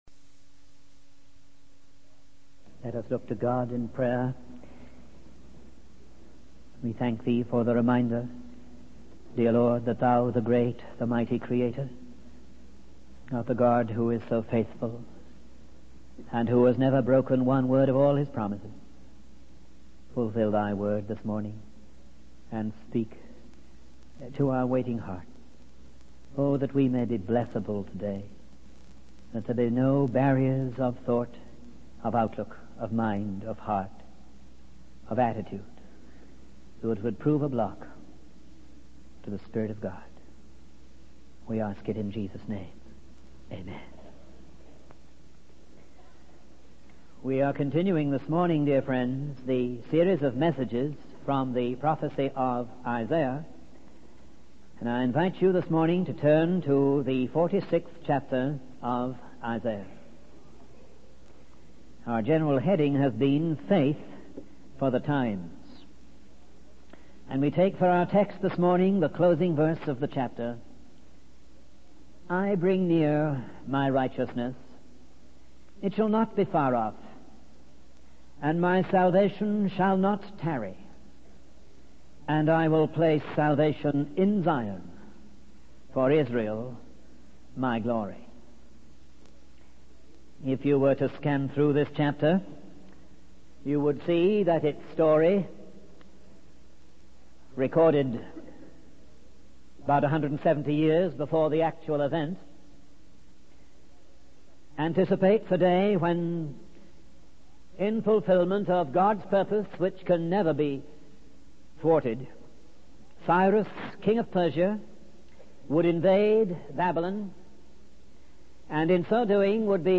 In this sermon, the speaker focuses on the parable of the sower from the eighth chapter of the gospel of Luke. He emphasizes the contrast between those who receive the word of God with joy but lack deep roots and fall away in times of temptation. The speaker then highlights the inadequacy of lip service to God when faced with crisis and the importance of true worship from the heart.